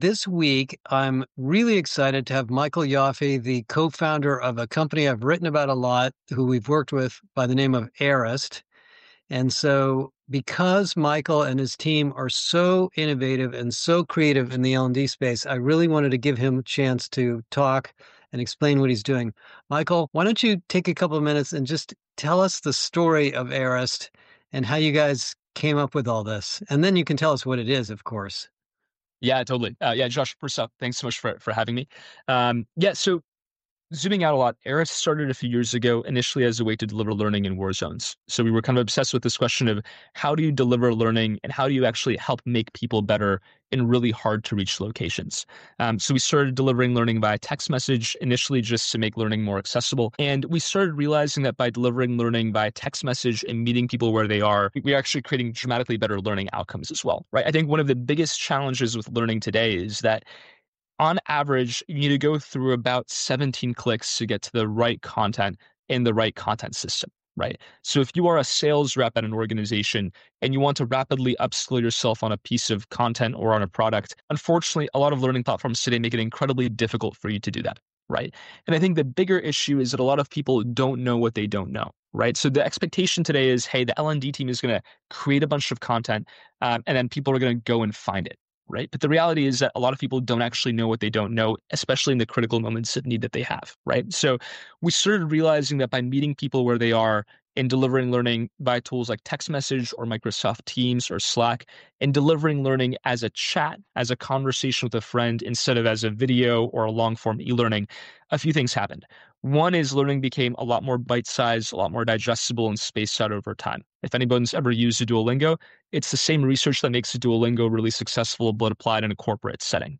This week I interview